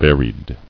[var·ied]